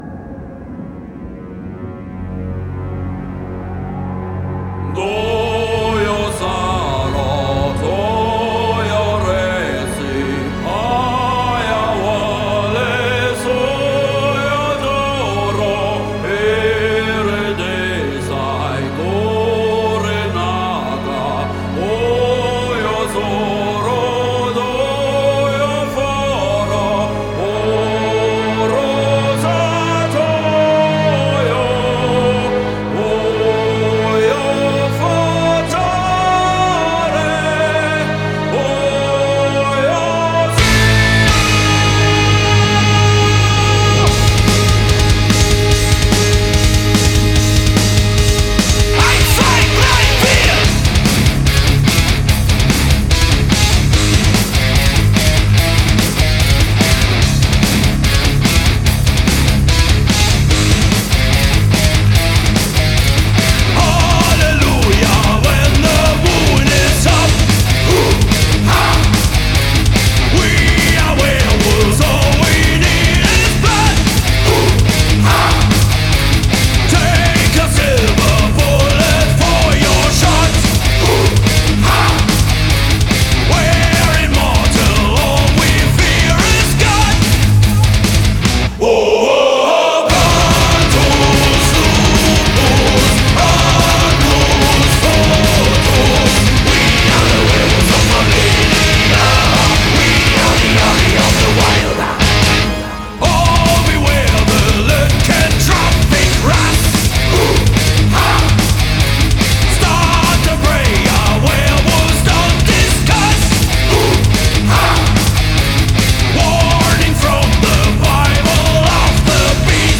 Metal
پاور متال